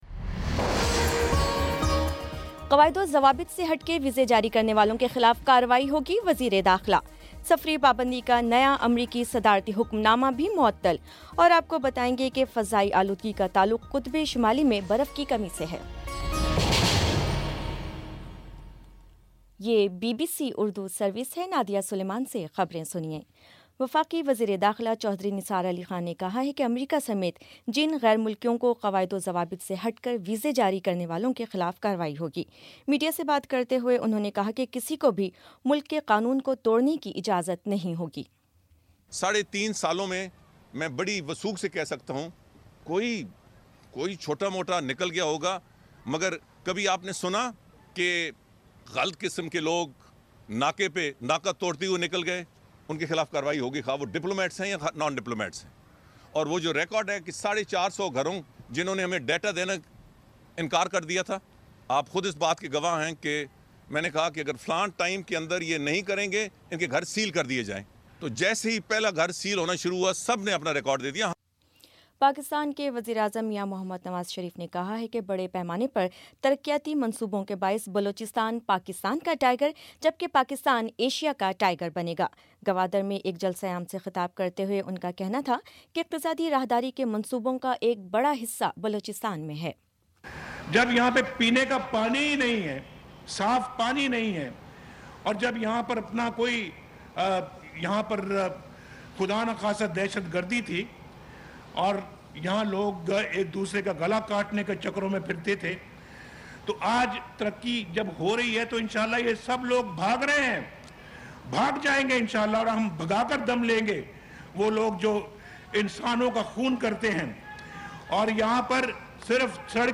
مارچ 16 : شام پانچ بجے کا نیوز بُلیٹن